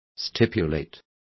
Complete with pronunciation of the translation of stipulate.